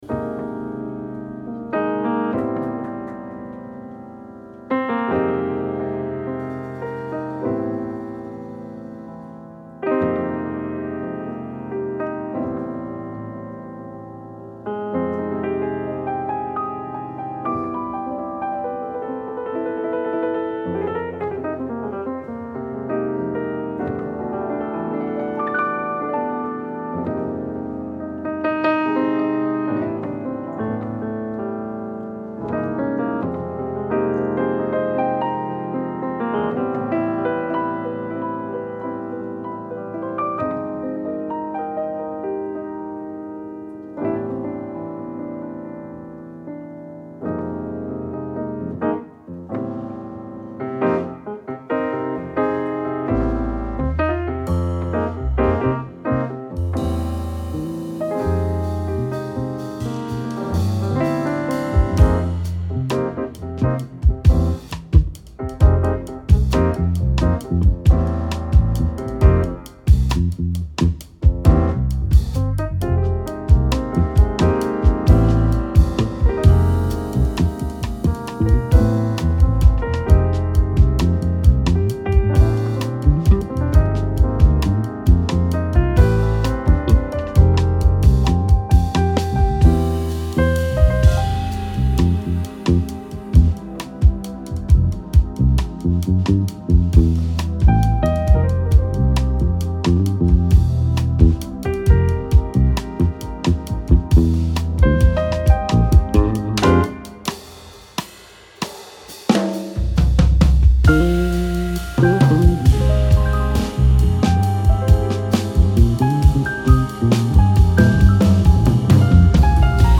Трек размещён в разделе Зарубежная музыка / Джаз.